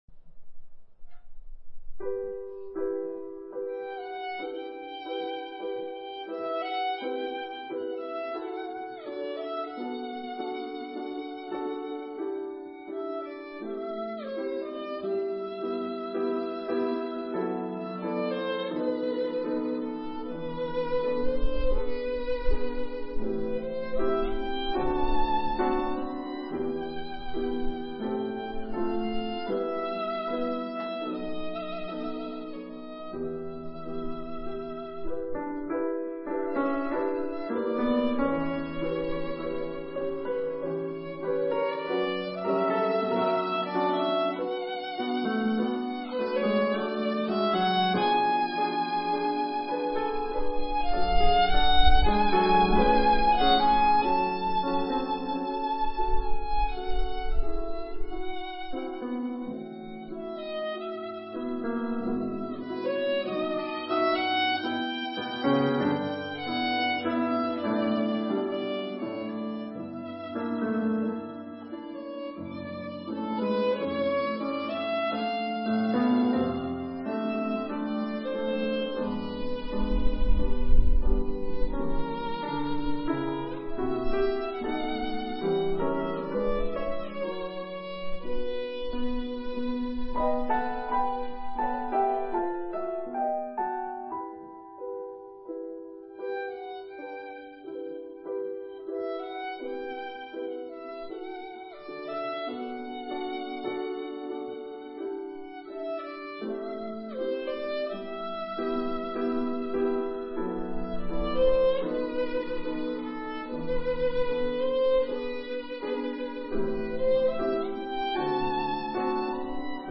violin
piano.
A t the beginning of the concert